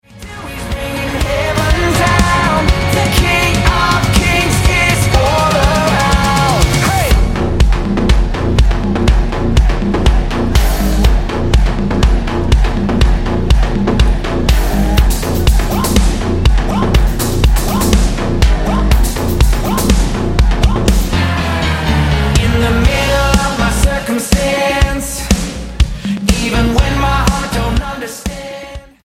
STYLE: Pop
starts with an attention-grabbing sub-bass riff